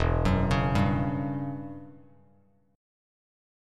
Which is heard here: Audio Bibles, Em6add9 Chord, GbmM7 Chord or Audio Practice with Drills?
Em6add9 Chord